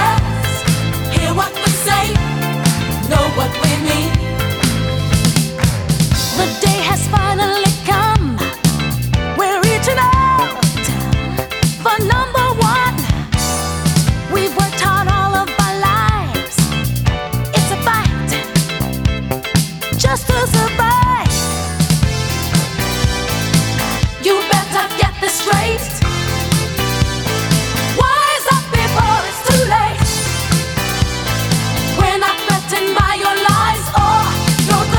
Жанр: R&B / Соул / Диско